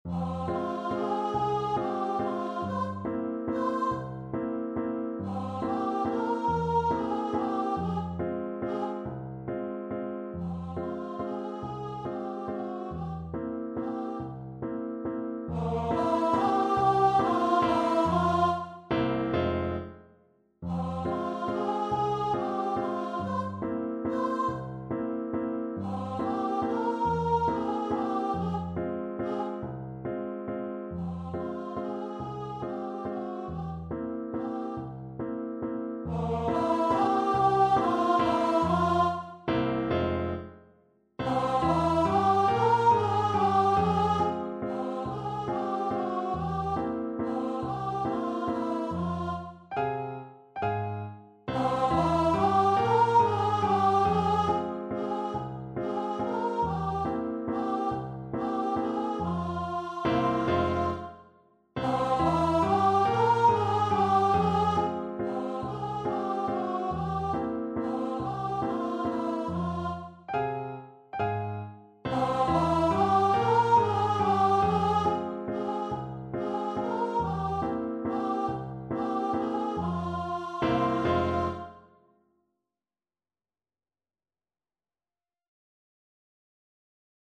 3/4 (View more 3/4 Music)
Tempo di Valse =140
Classical (View more Classical Voice Music)